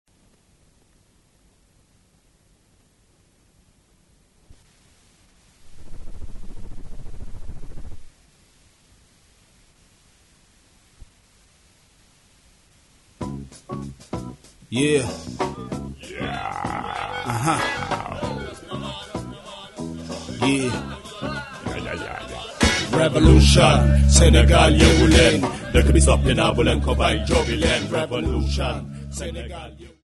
Rap (Music)
Folk music
sound recording-musical